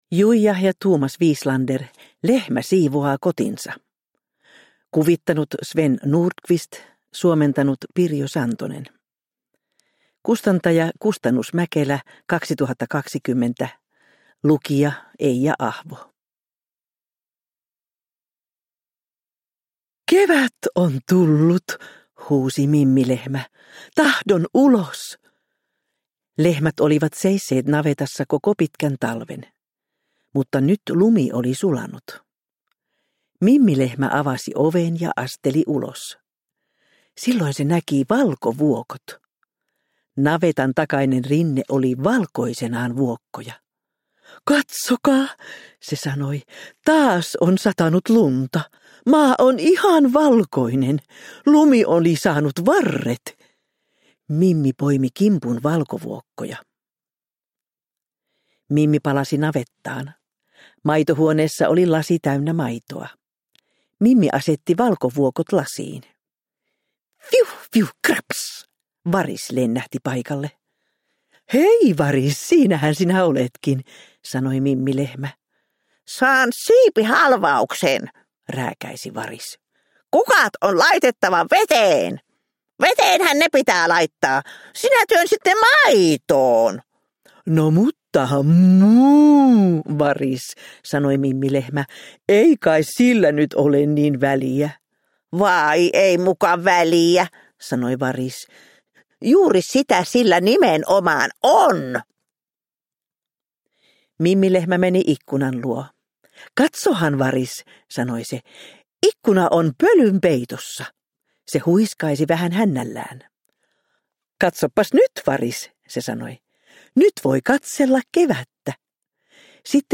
Lehmä siivoaa kotinsa – Ljudbok – Laddas ner